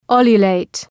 Προφορά
{‘ju:ljə,leıt}